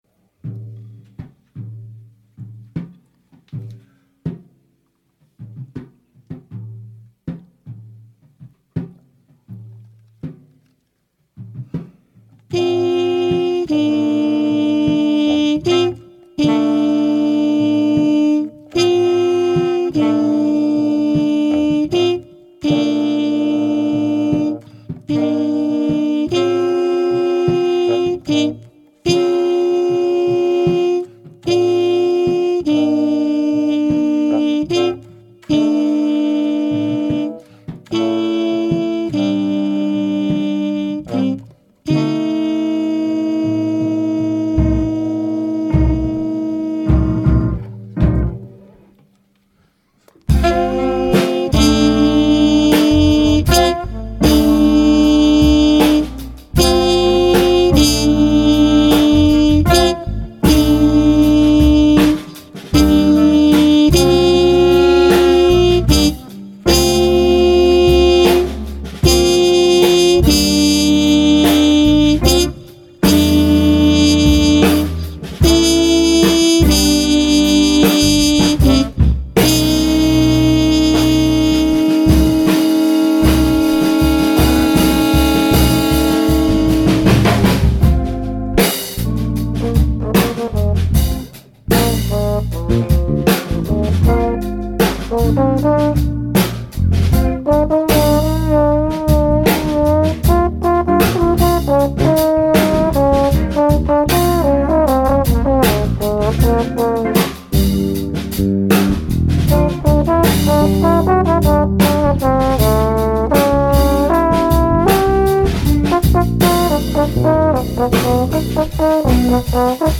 Voicing: Jazz Combo